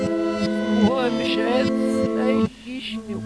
Backwards